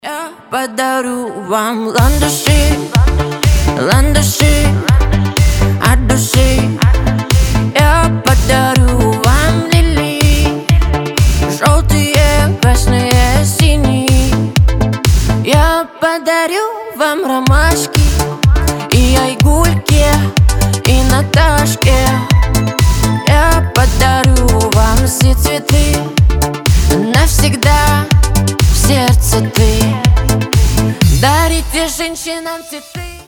Рингтоны техно